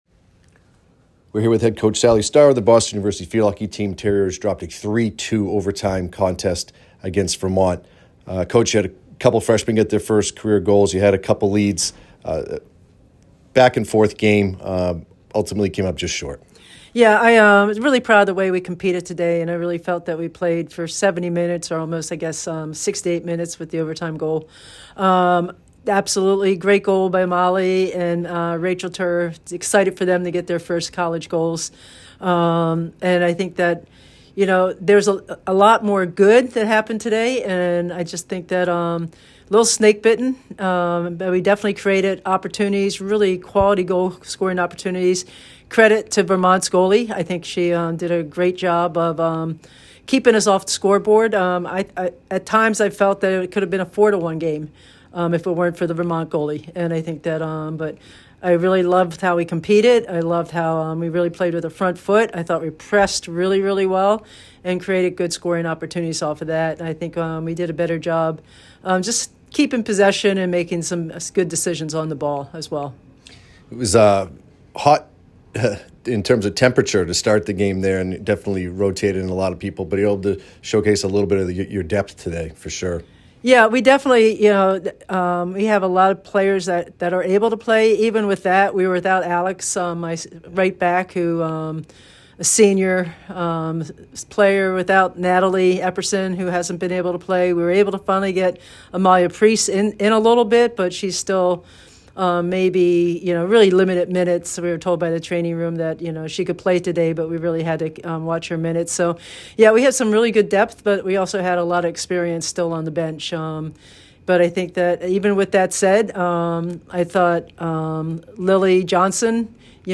Field Hockey / Vermont Postgame Interview